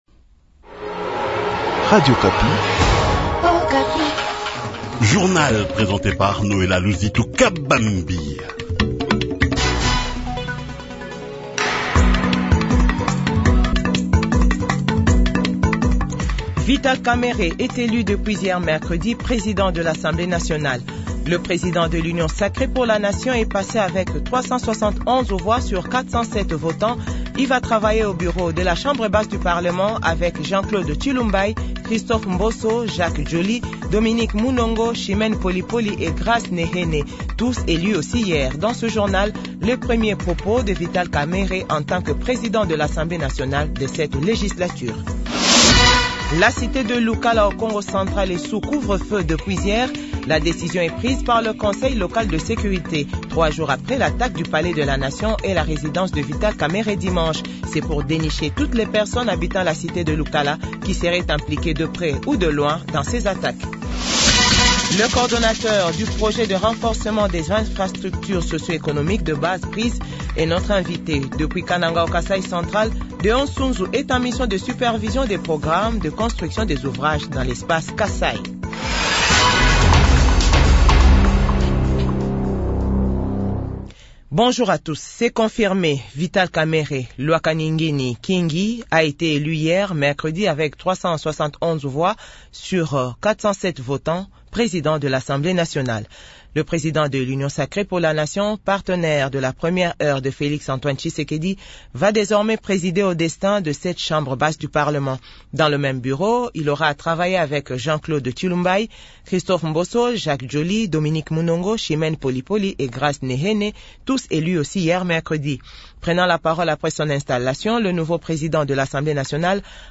JOURNAL FRANCAIS 6H00 - 7H00